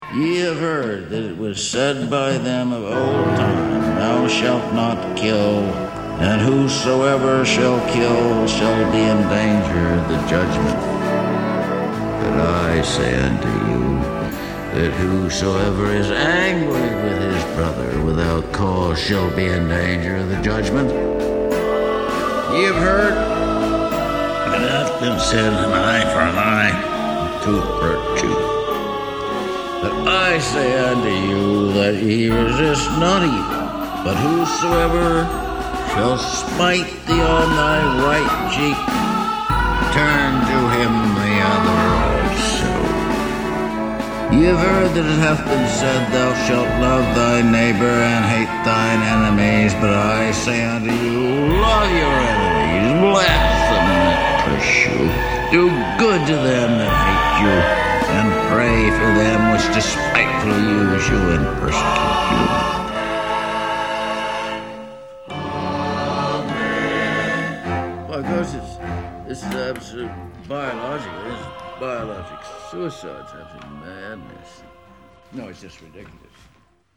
soggy recitation of The Sermon on the Mount from "Dead City Radio" and was comforted and conflicted.
At the end you hear the old codger muttering: "Of course it's absolute, it's biological suicide. It's absolute madness, no, it's just ridiculous."